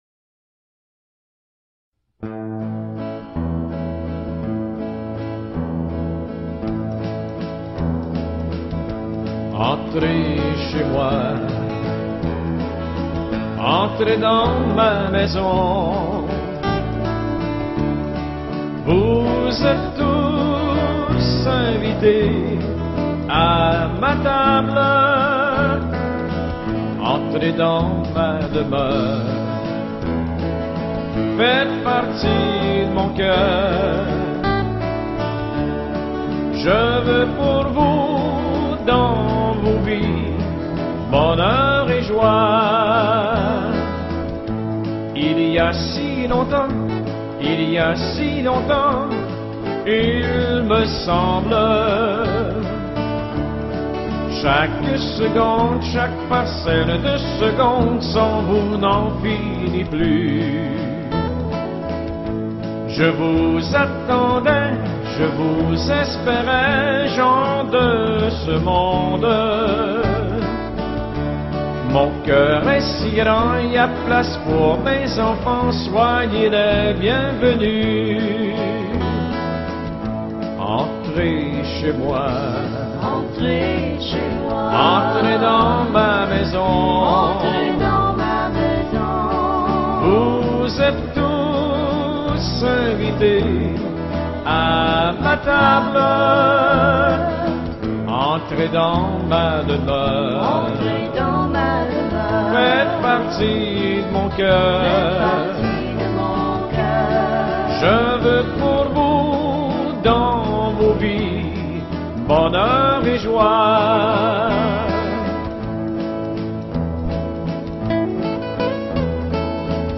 126e Cursillo Jeunesse  -  19 au 22 juin 2014